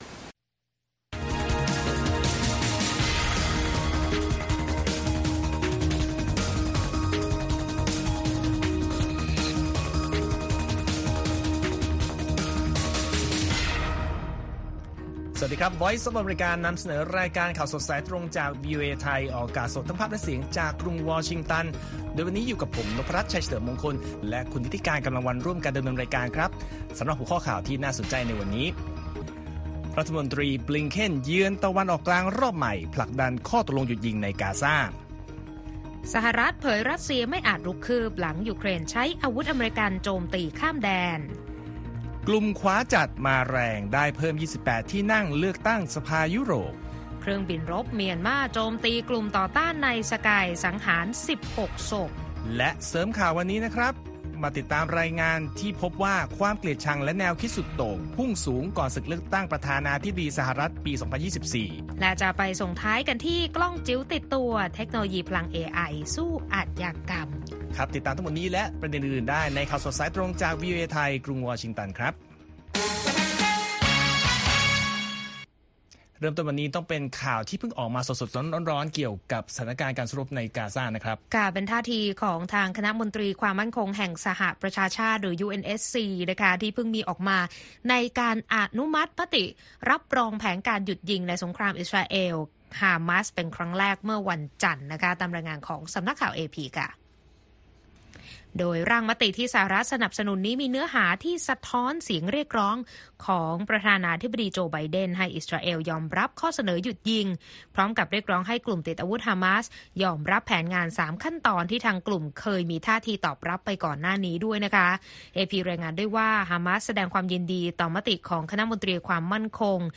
ข่าวสดสายตรงจากวีโอเอไทย 8:30–9:00 น. วันอังคารที่ 11 มิถุนายน 2567